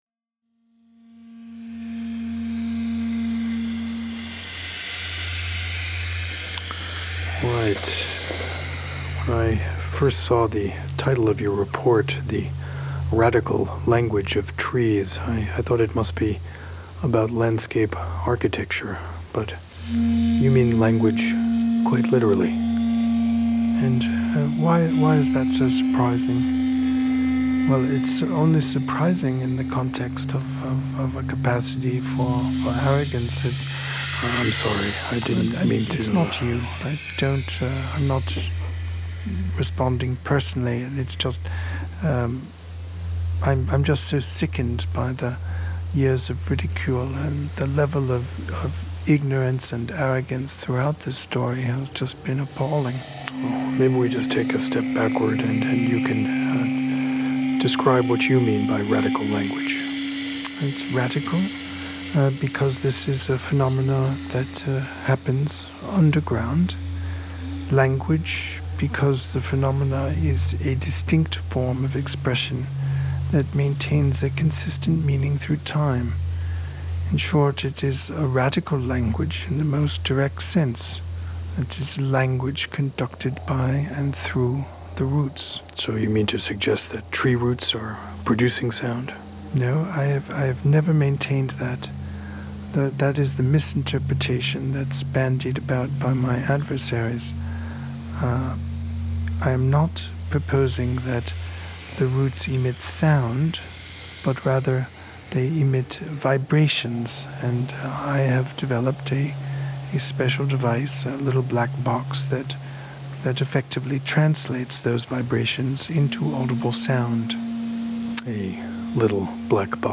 speculative interview dating from the 1990s